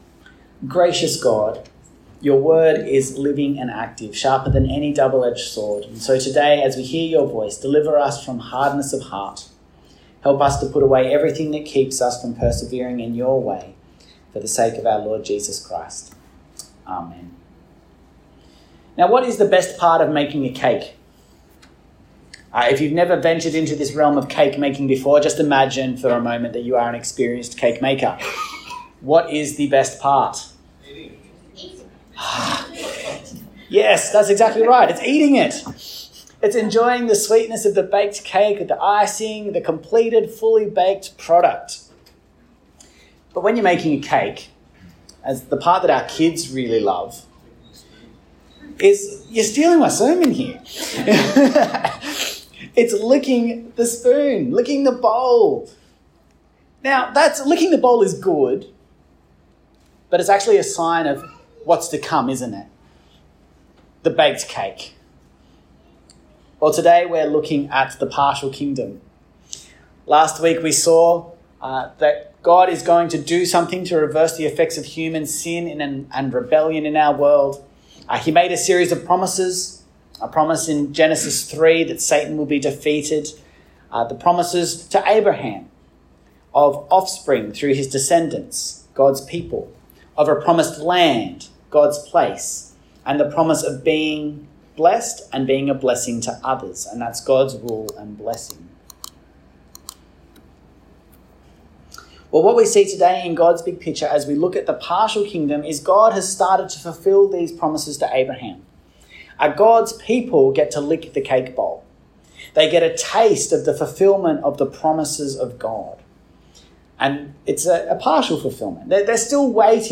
The Partial Kingdom - Sermon.mp3